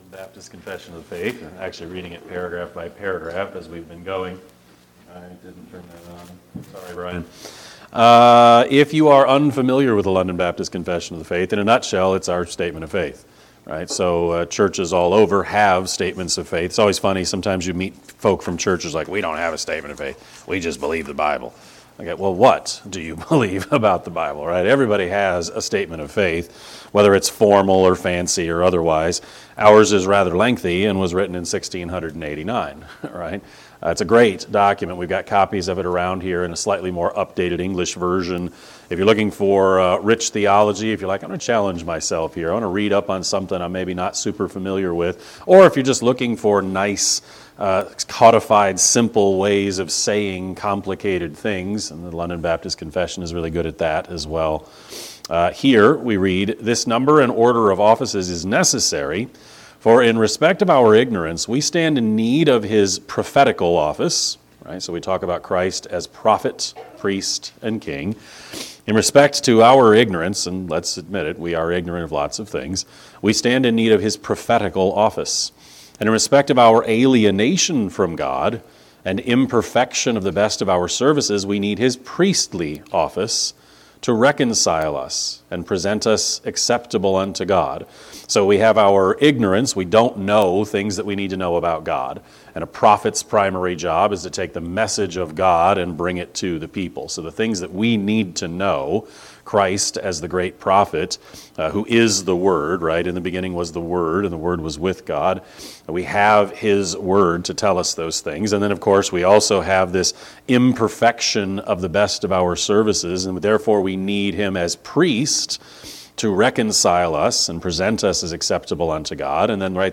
Sermon-2-1-26Edit.mp3